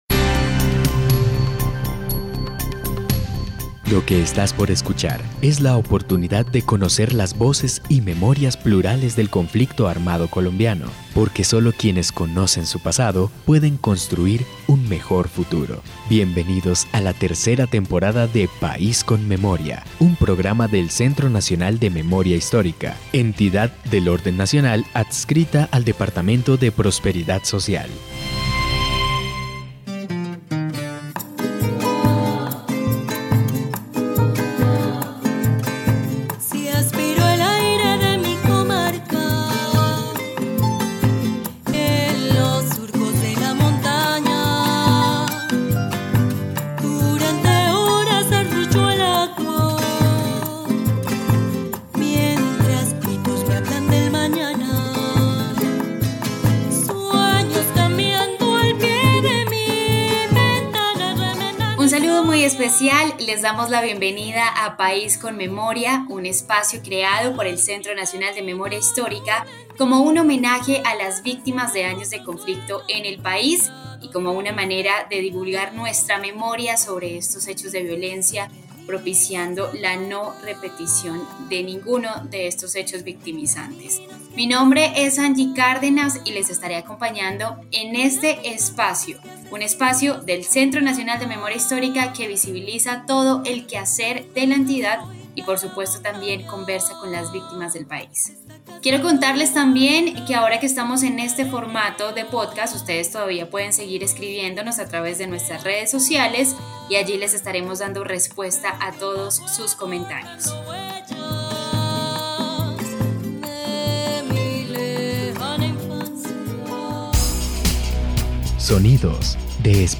Charla sobre la conmemoración del día nacional de los Archivos y Archivistas.